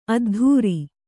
♪ addhūri